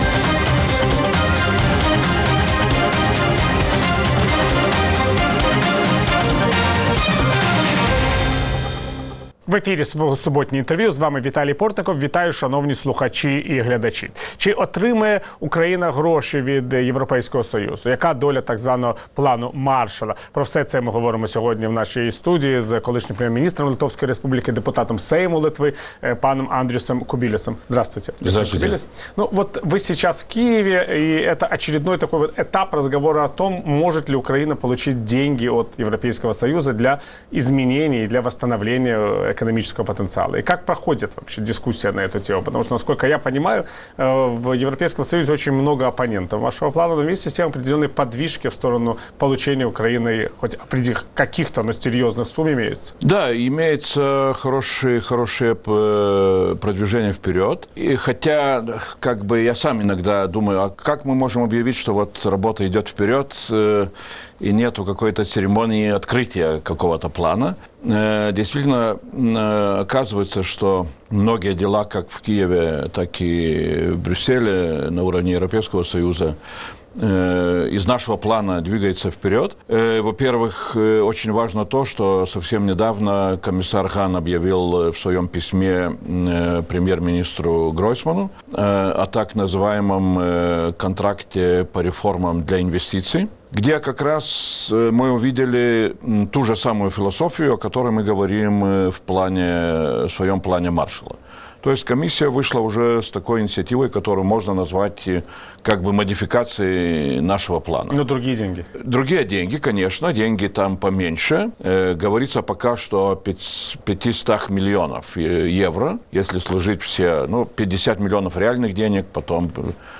Суботнє інтерв’ю - Андрюс Кубілюс, колишній прем’єр-міністр Литви